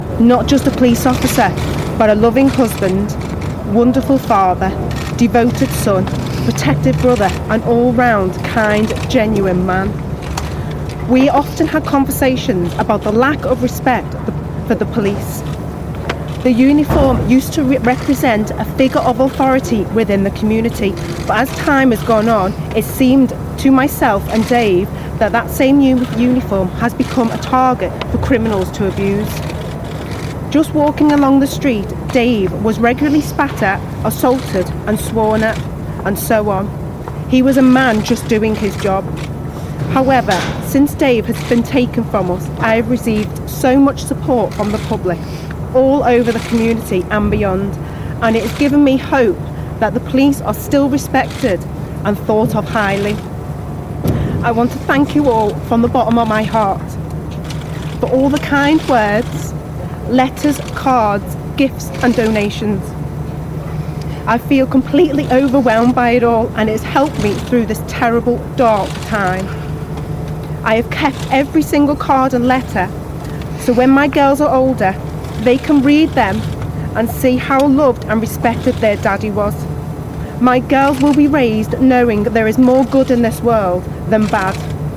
reads out a statement outside court.